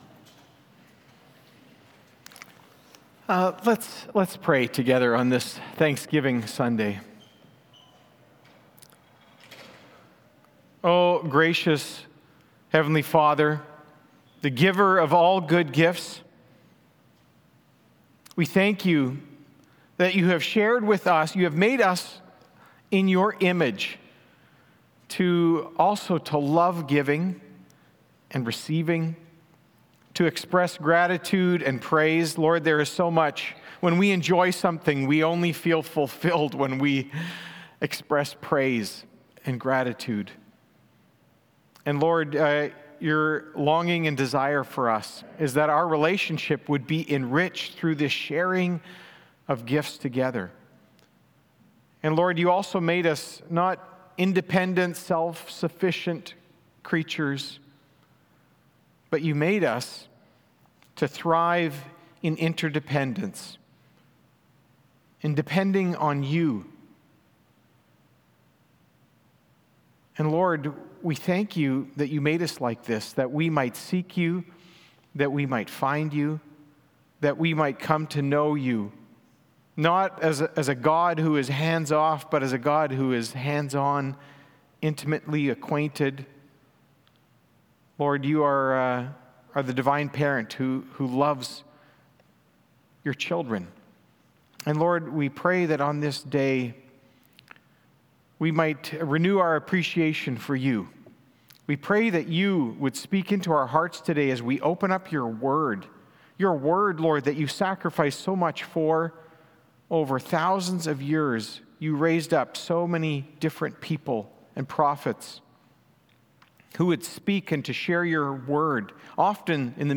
Sermons | Eagle Ridge Bible Fellowship